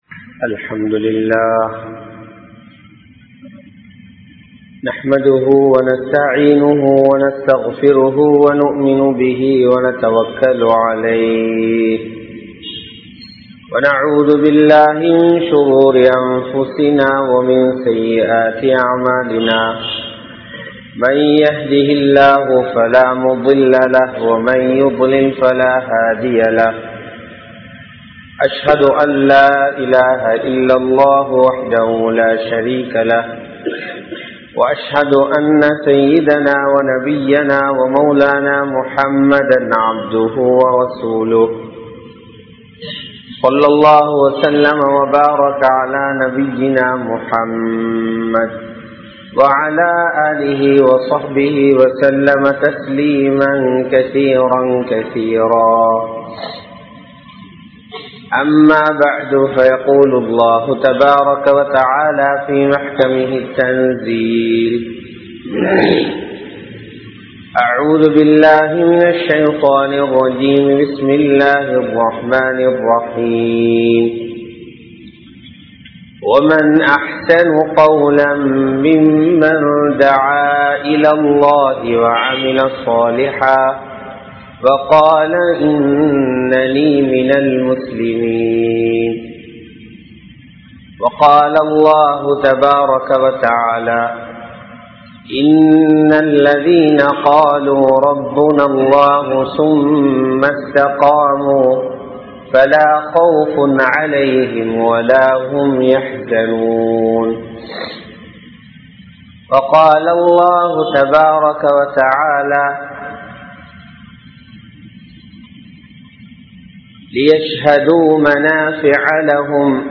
Dhawah Ethatku? (தஃவா எதற்கு?) | Audio Bayans | All Ceylon Muslim Youth Community | Addalaichenai